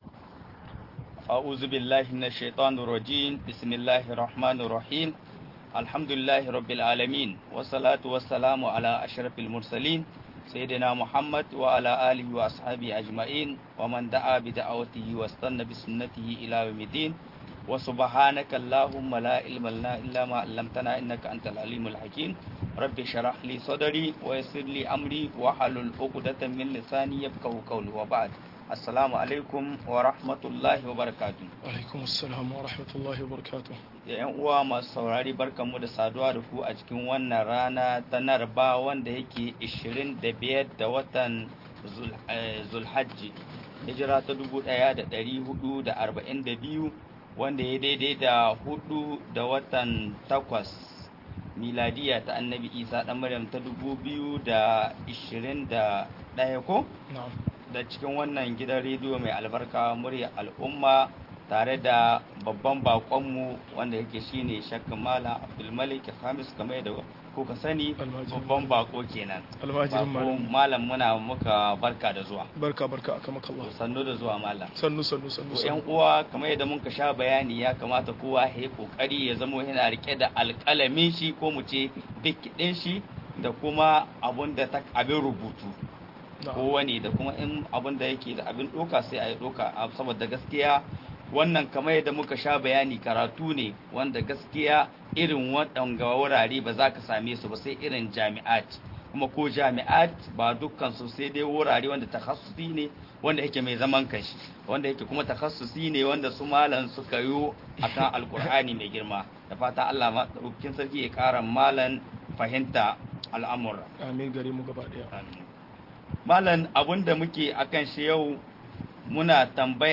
ilimin Alkur'ani-01 - MUHADARA